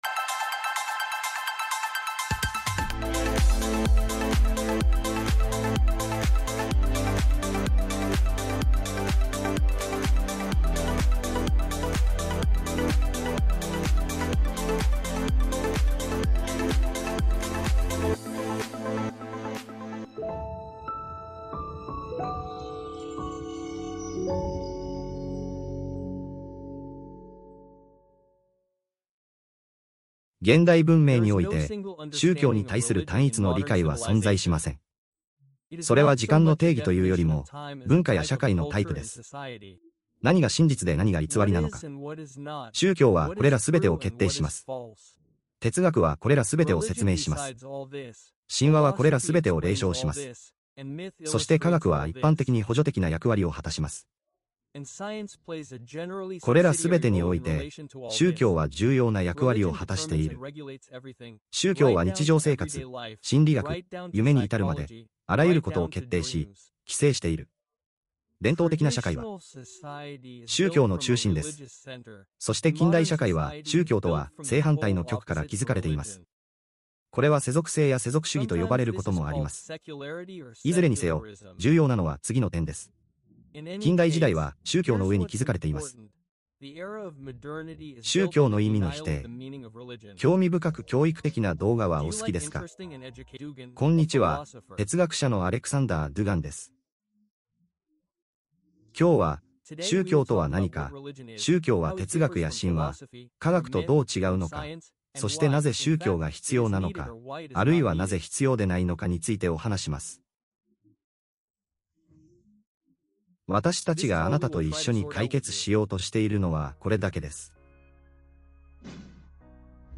哲学者アレクサンダー・ドゥギンが、ドストエフスキー・レクチャー・チャンネルの新しいレクチャーで、社会の歴史的発展という文脈における宗教の概念について明らかにします。